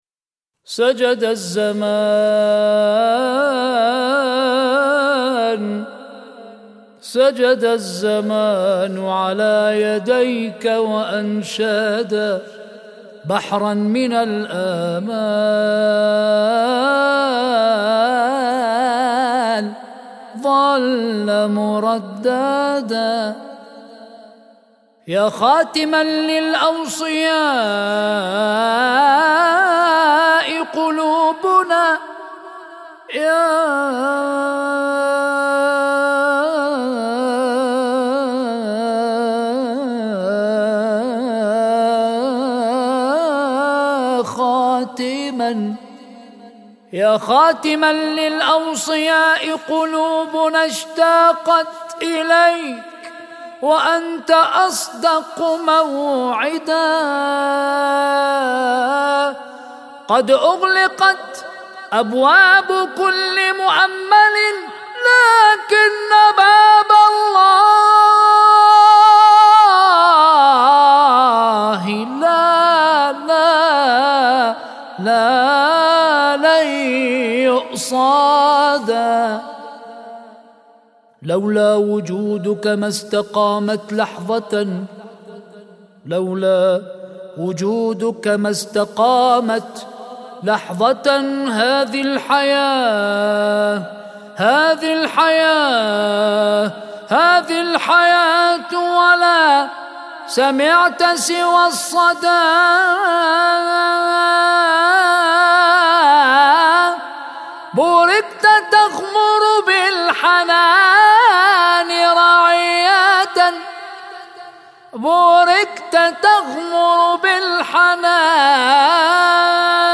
سجد الزمان على يديك وأنشدا - ولادة الإمام المهدي (عج) - مدائح